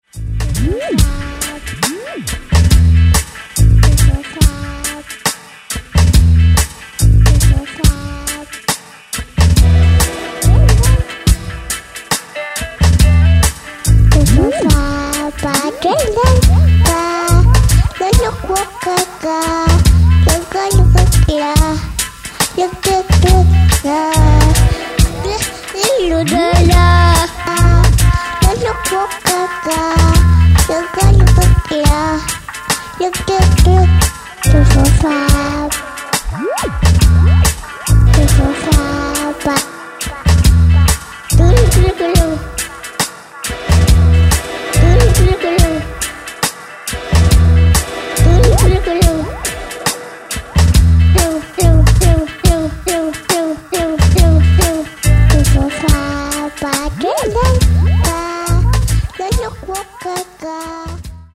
Catalogue -> Rock & Alternative -> Reggae